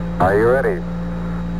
Radio voices soundbank 3
Free MP3 radio voices soundbank 3